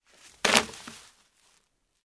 Звуки скакалки
Звук упавшей на пол скакалки